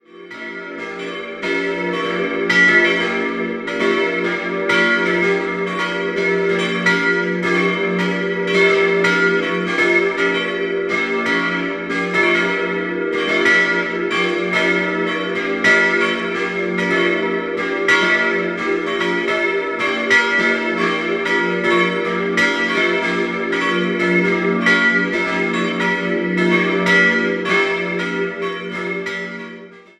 Eine umfassende Umgestaltung des Inneren erfolgte im Jahr 1790. 4-stimmiges Geläut: e'-gis'-h'-d'' Die vier Gussstahlglocken wurden 1922 von den Böhlerwerken in Kapfenberg gegossen.